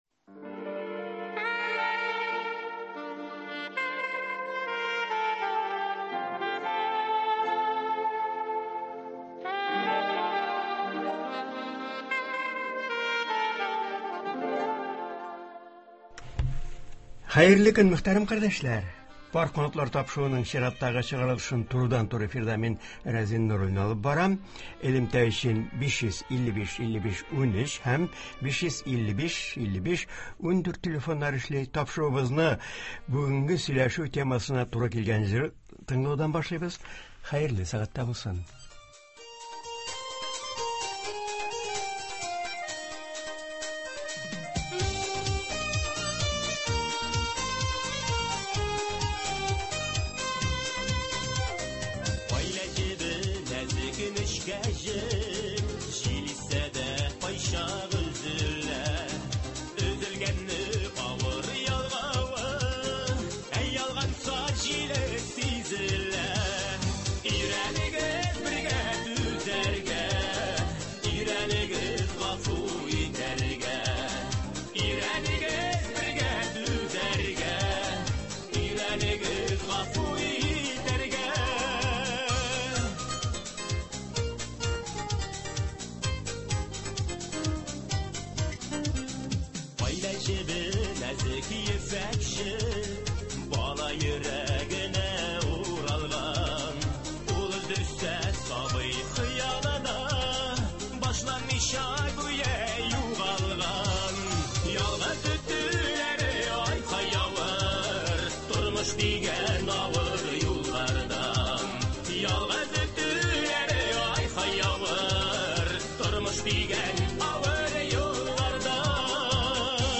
Киләсе ел өчен матбугатка язылу барган бу көннәрдә журнал хезмәткәрләре турыдан-туры эфирда үзләренең уй-фикерләре белән уртаклашачак, телефон элемтәсе аша килгән сорауларга җавап бирәчәк.